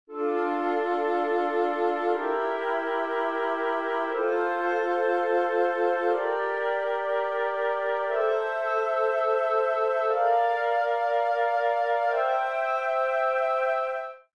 Con lo stesso procedimento, costruiamo ora le triadi su ogni grado della scala di Re minore naturale: